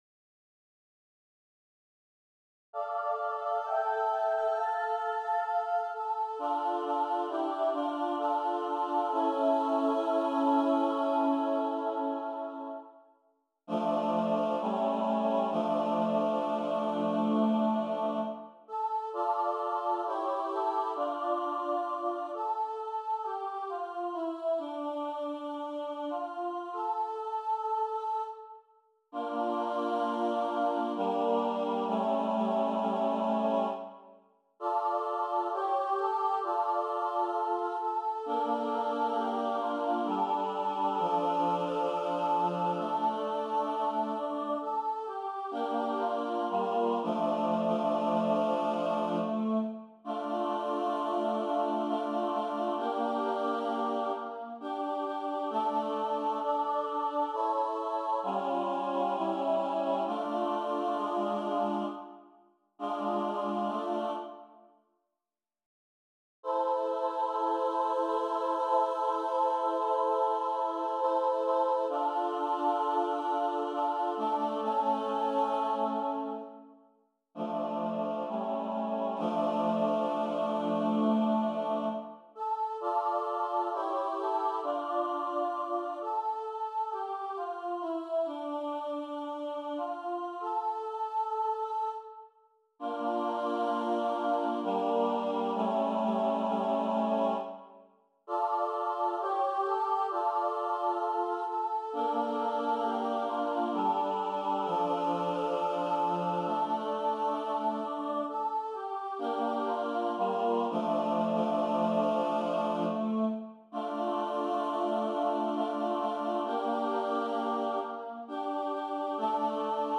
SSA Trio a cappella